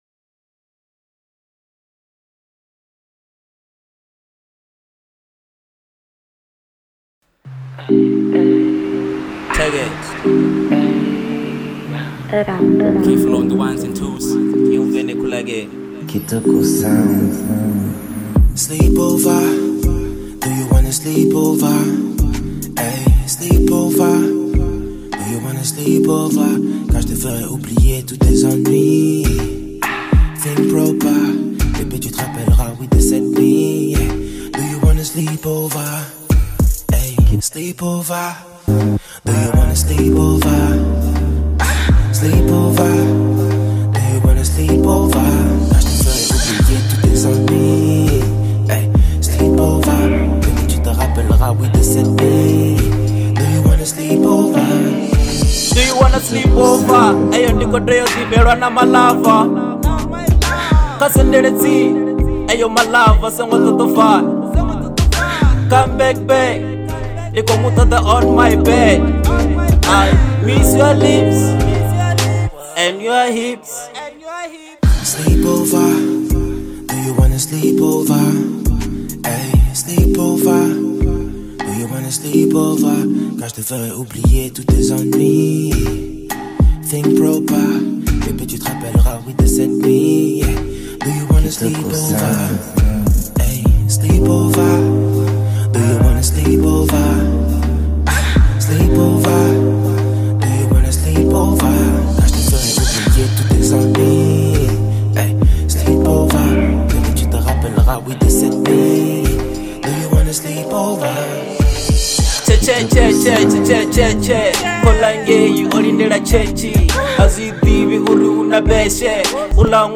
02:45 Genre : Venrap Size